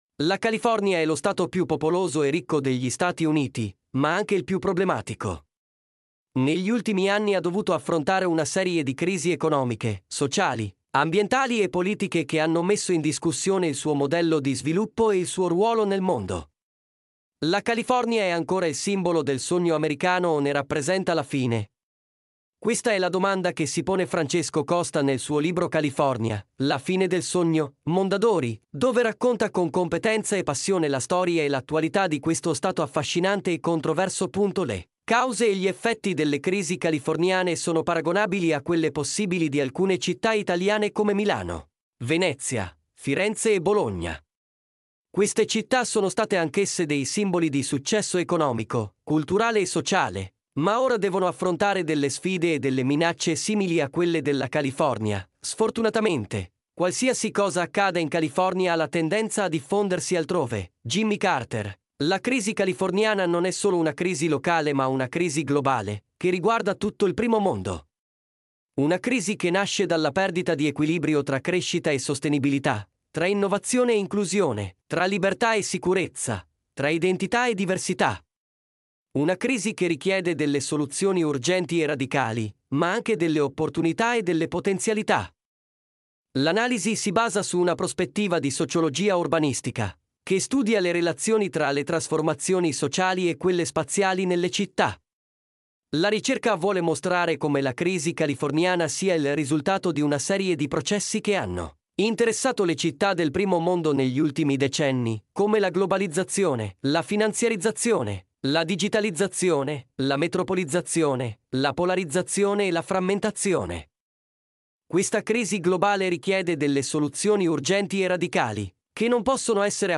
Se vuoi ascoltare in podcast quest’articolo puoi farlo qui sotto premendo il tasto play: tempo di ascolto 9.47 minuti
mp3-output-ttsfreedotcom-6_Y3N0kuWT.mp3